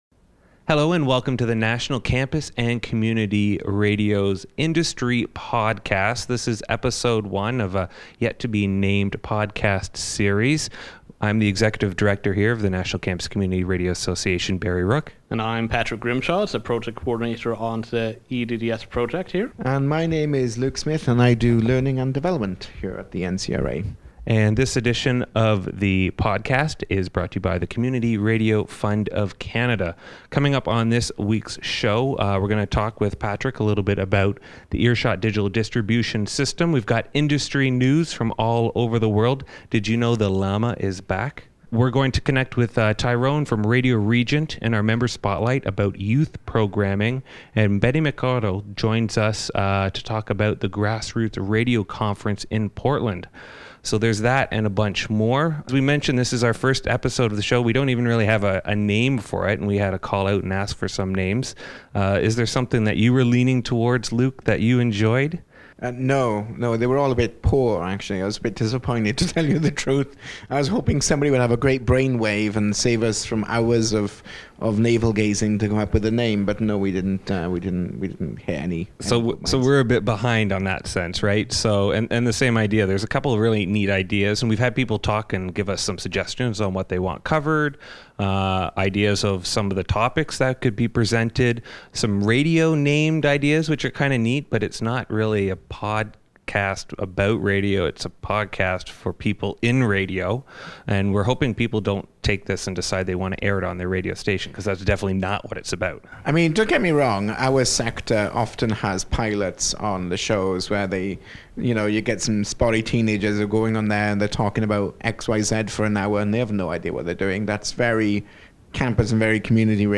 Voice Over track with side-chain ducking of Music Track with 25dB of gain reduction.